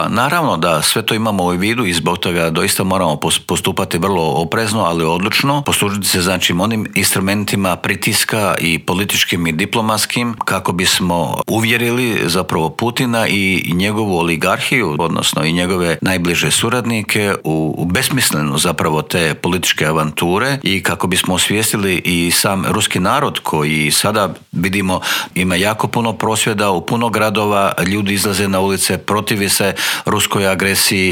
Gostujući u Intervjuu tjedna Media servisa ministar vanjskih i europskih poslova Gordan Grlić Radman nam je otkrio da je u Ukrajini 23 Hrvata, a da njih 10 ne želi napustiti tu zemlju.